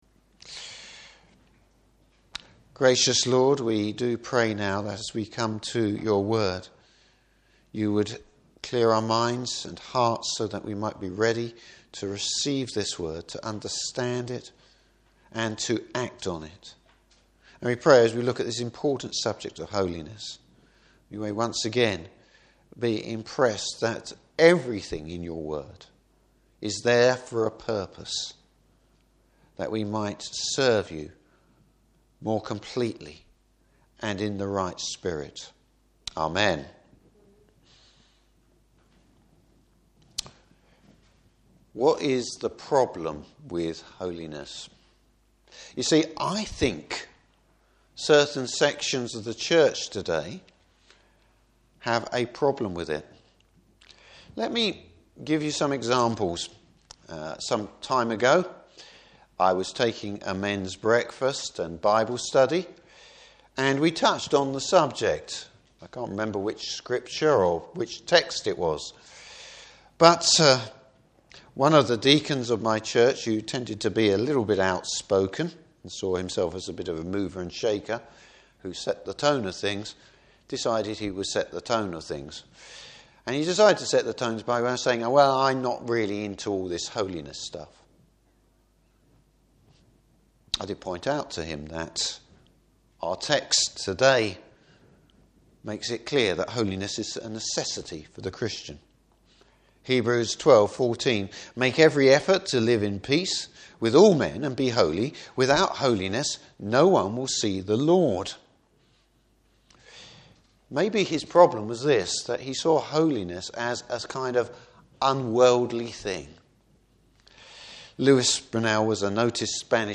Service Type: Morning Service Why is it essential God’s people seek to live hoy lives?